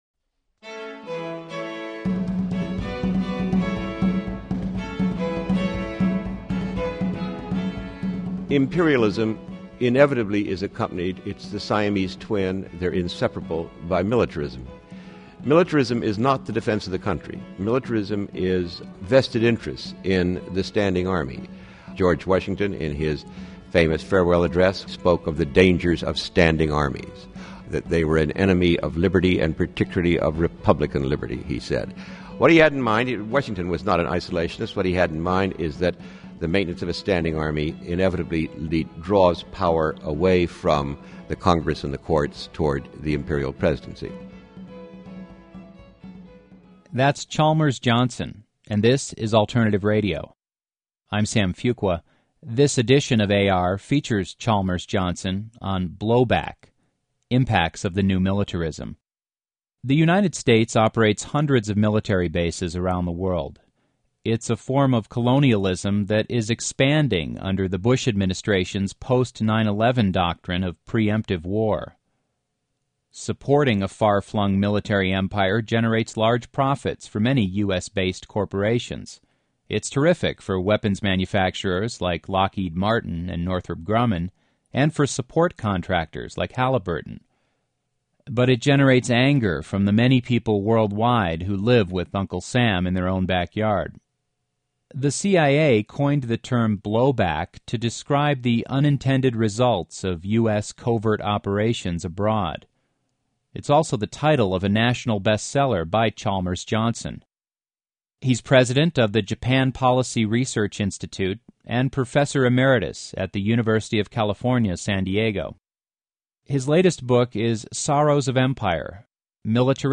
Blowback is a 2004 lecture by Chalmers Johnson on the US Empire. Drawing comparisons with Rome, Johnson describes the end of the Republic through imperialism and militarism.